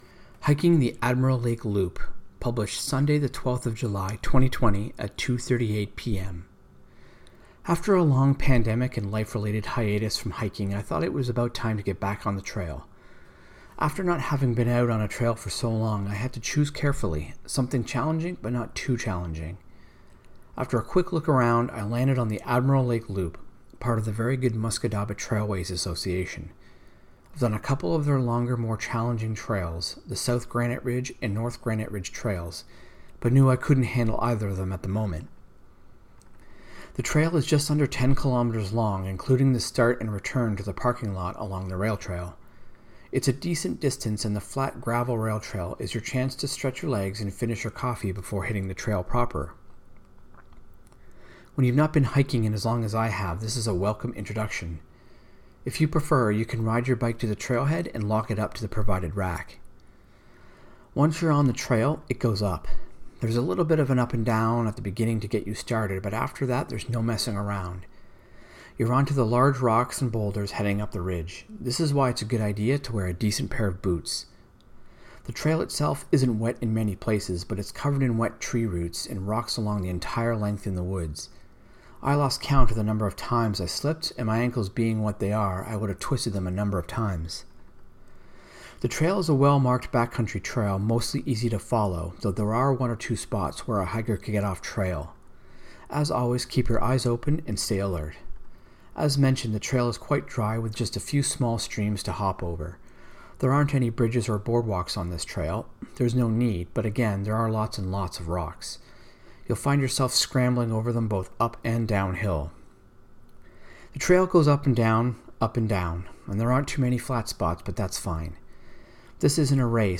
Listen to me read the text of this post: